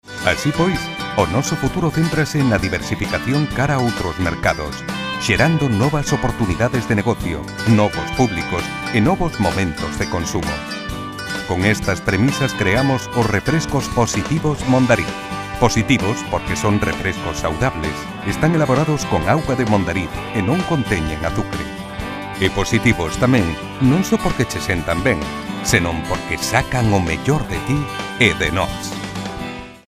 Dialekt 1: kastilisch
Professional voice over actor since 1990.